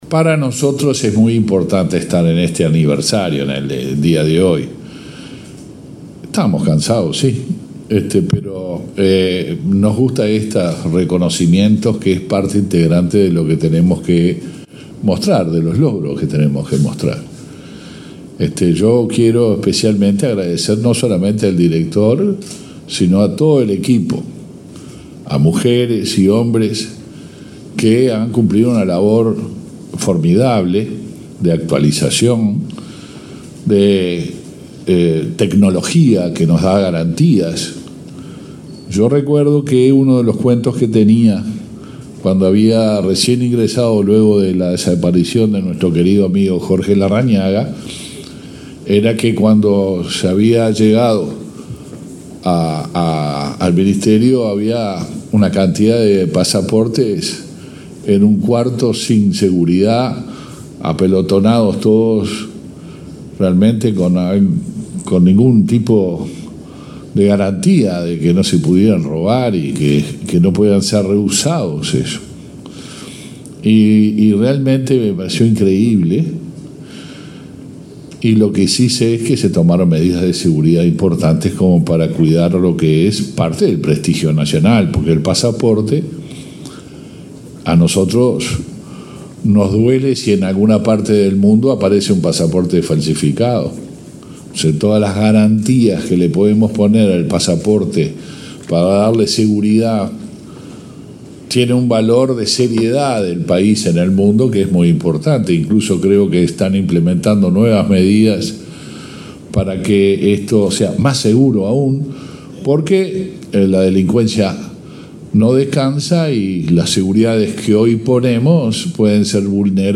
Palabras del ministro del Interior, Luis Alberto Heber
El ministro del Interior, Luis Alberto Heber, participó en el acto aniversario de la Dirección Nacional de Identificación Civil.